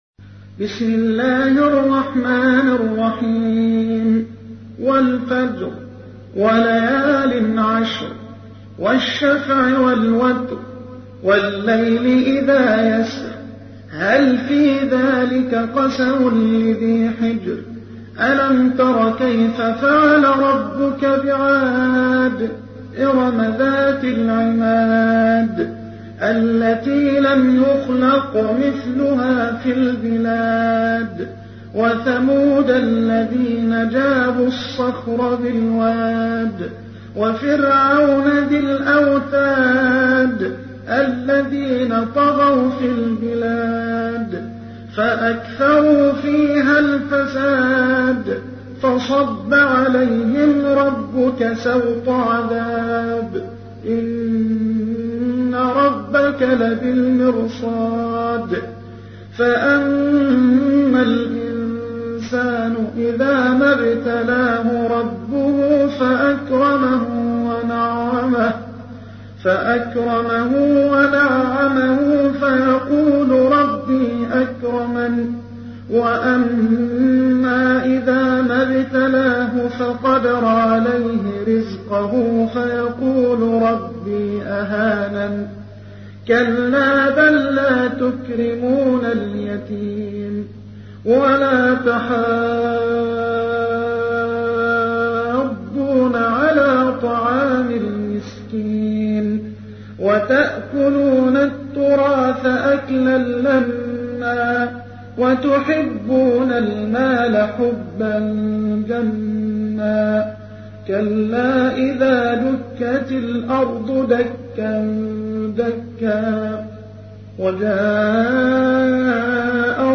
تحميل : 89. سورة الفجر / القارئ محمد حسان / القرآن الكريم / موقع يا حسين